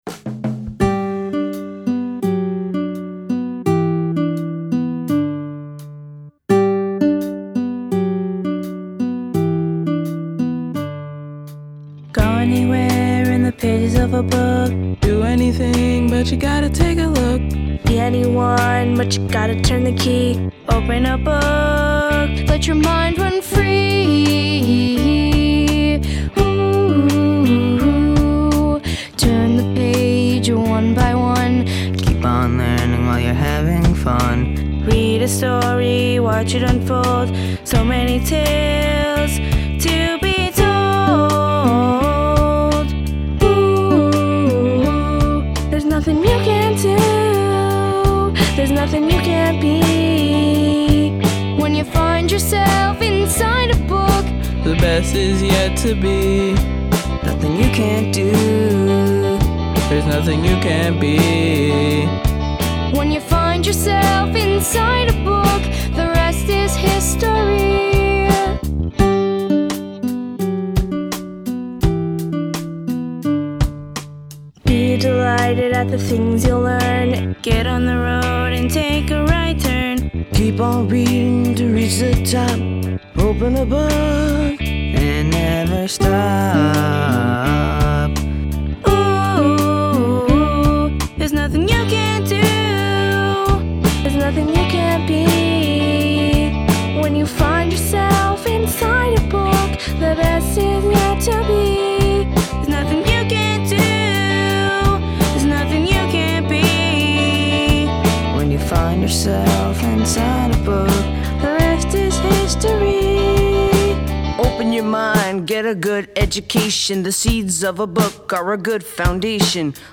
(Audio) Apr 29, 2011 Recorded by Hudson children at Riverfront Studios in Hudson.